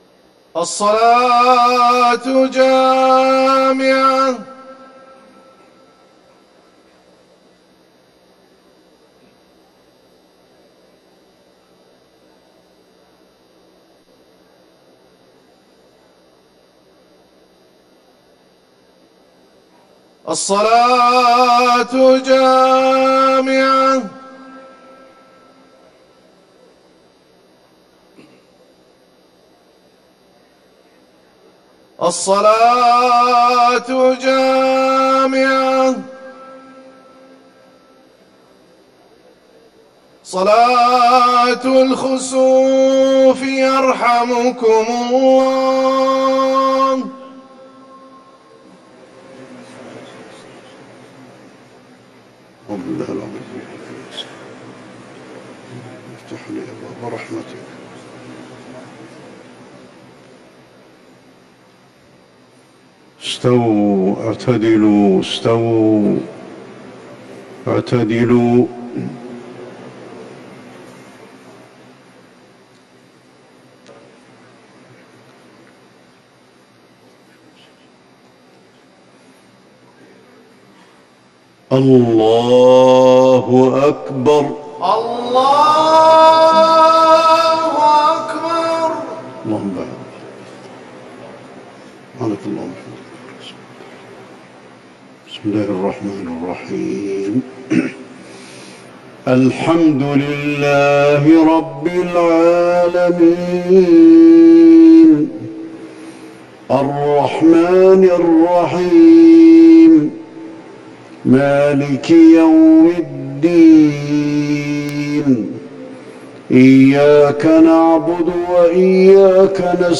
صلاة الخسوف 13 ذو القعدة 1440هـ من سور يس و الدخان و ق و الطور > الكسوف 🕌 > المزيد - تلاوات الحرمين